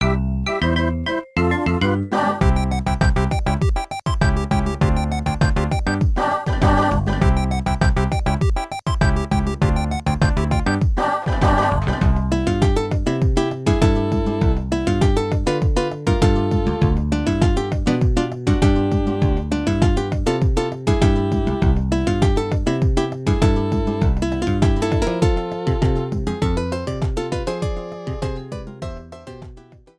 Fade out added